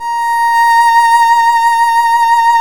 Index of /90_sSampleCDs/Roland - String Master Series/STR_Violin 1 vb/STR_Vln1 % + dyn
STR VLN MT0N.wav